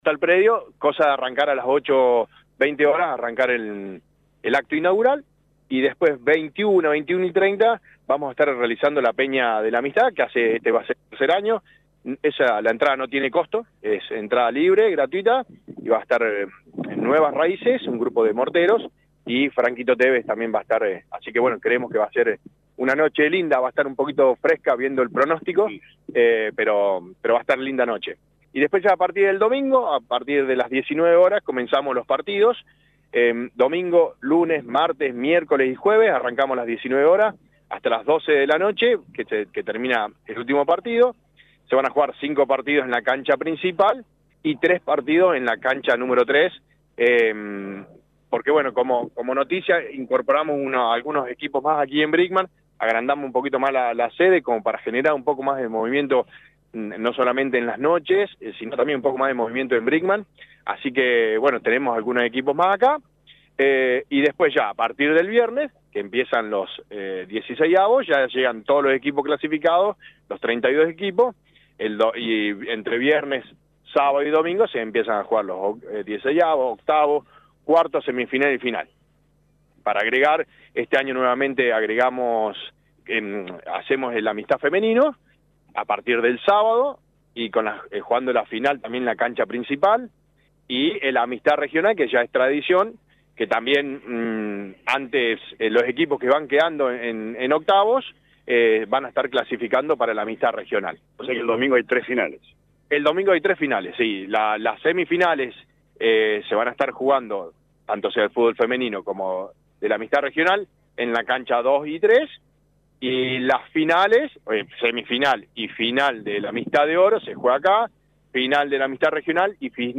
A horas del inicio del torneo de fútbol infantil más grande del país, LA RADIO 102.9 Fm visitó el predio deportivo de Club Centro Social y Deportivo Brinkmann